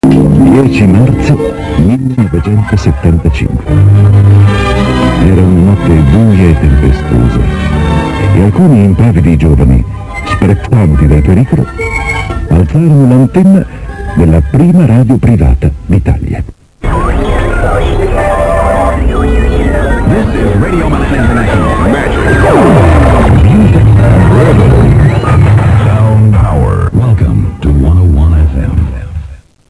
Un ronzio, una portante  ed infine un brano straniero, si diffusero nell'etere milanese sulla frequenza 101.000 MHz